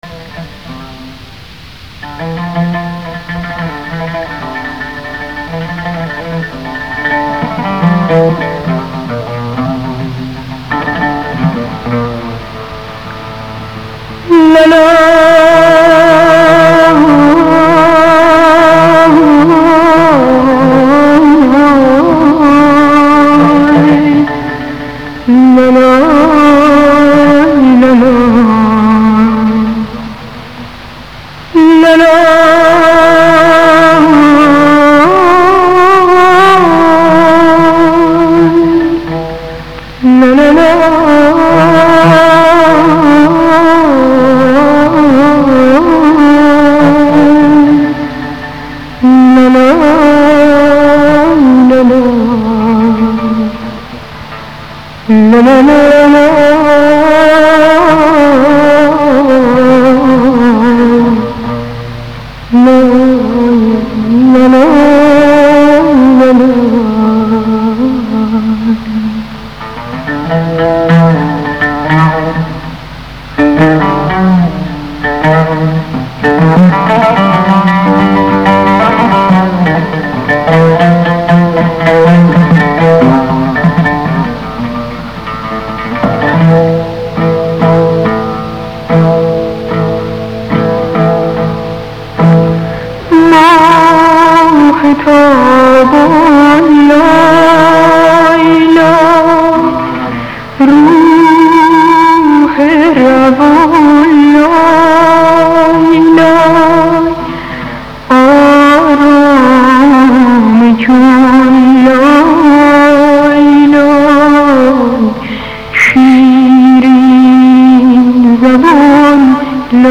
اشعار امری قدیمی